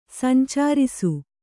♪ sancārisu